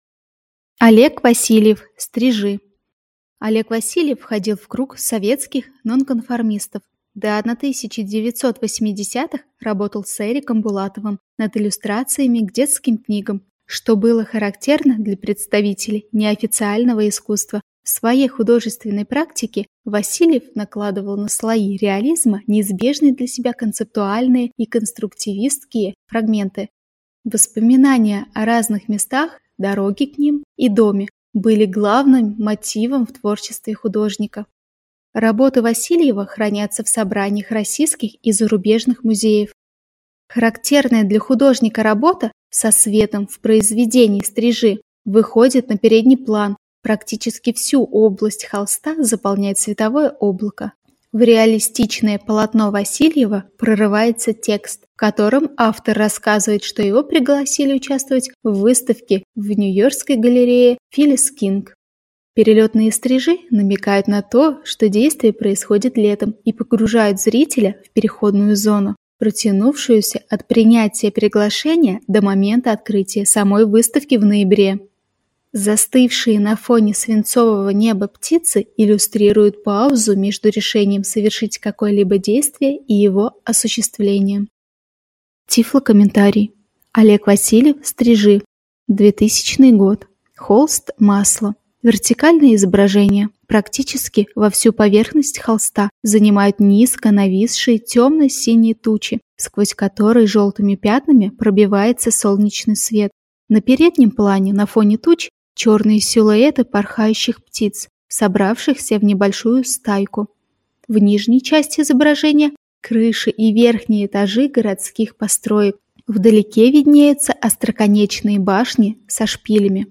Тифлокомментарий к картине Олега Васильева "Стрижи"